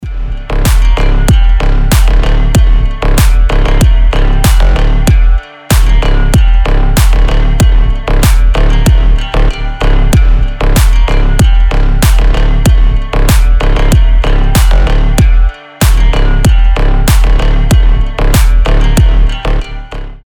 мощные басы
без слов
качающие
G-House
Стиль: G-house